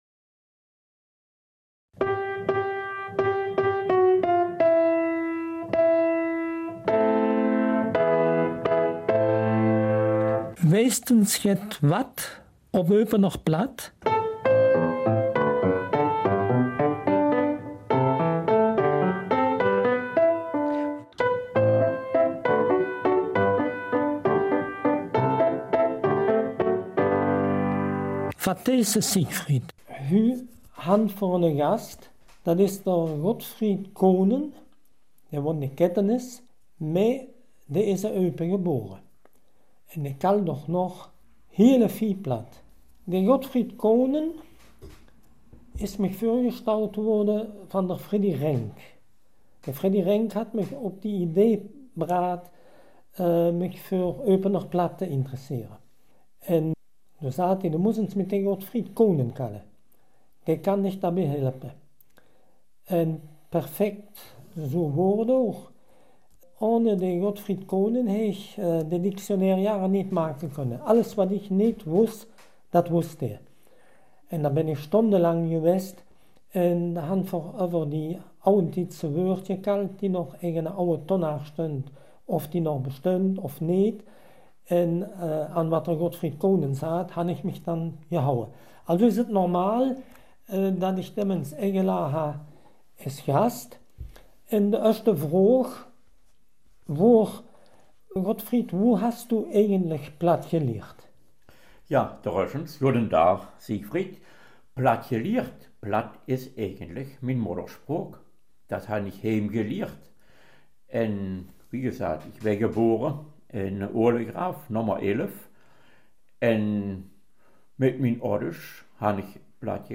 Eupener Mundart